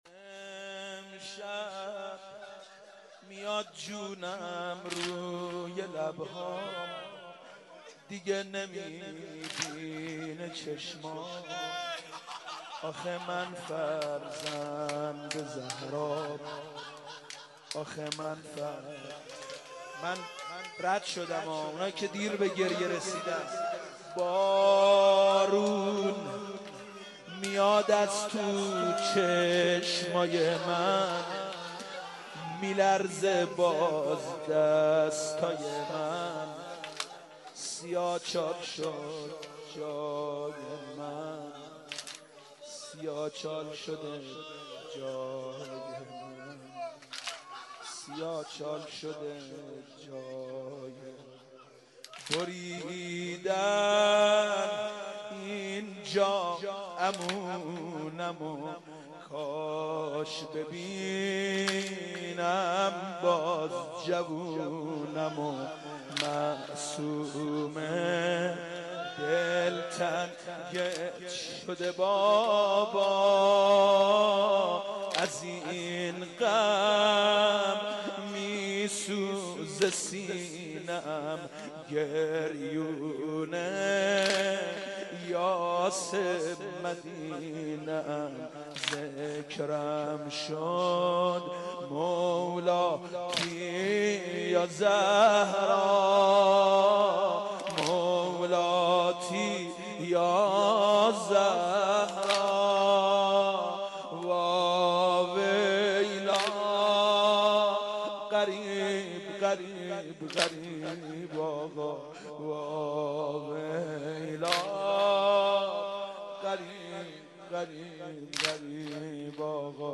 مداحی حاج سید مهدی میرداماد به مناسبت شهادت امام موسی کاظم(ع)